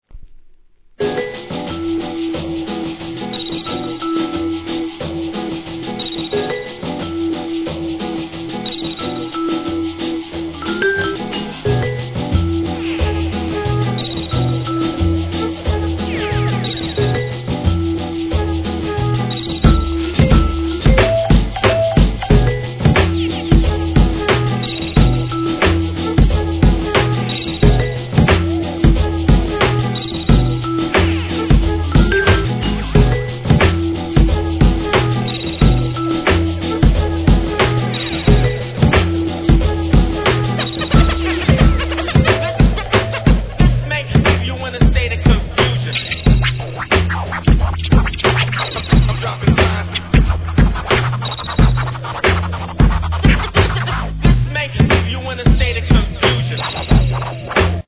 HIP HOP/R&B
本作も怒渋いトラックにスクラッチをはめ込みかっけ〜す!!